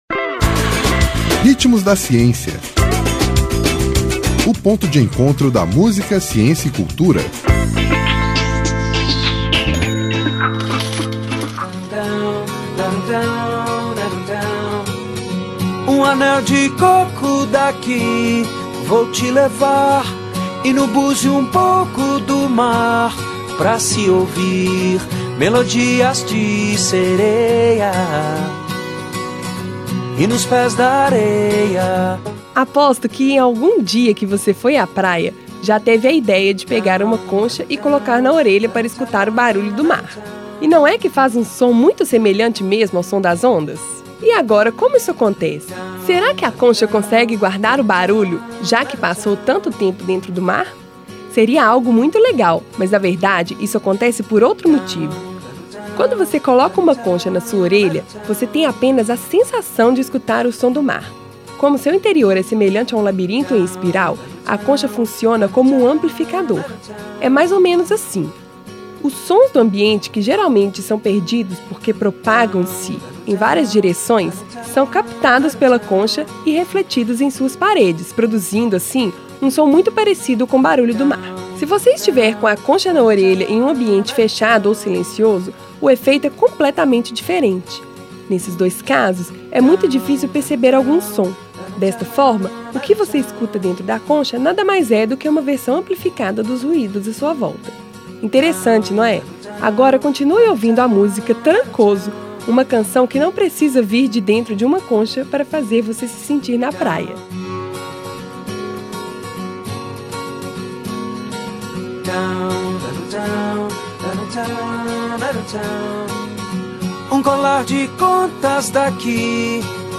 Nome da música: Trancoso
Intérprete: Skank